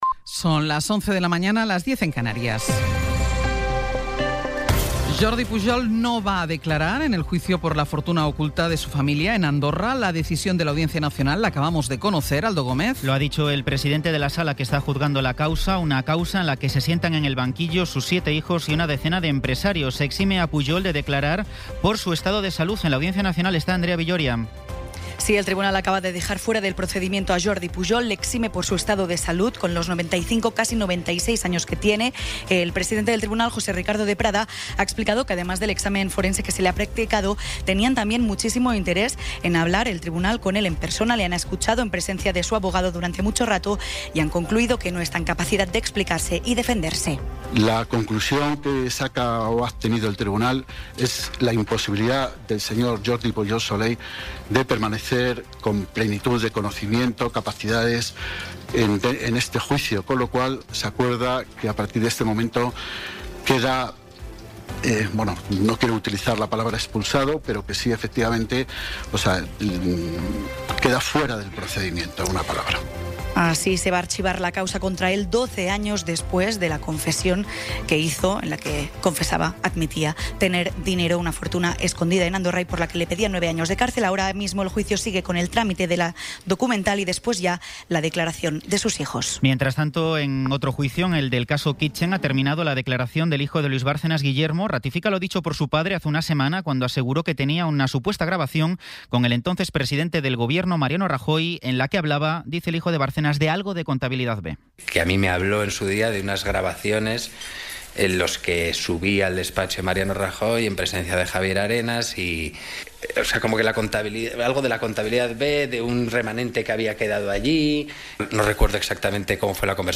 Resumen informativo con las noticias más destacadas del 27 de abril de 2026 a las once de la mañana.